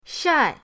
shai4.mp3